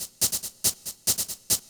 K-1 Shaker.wav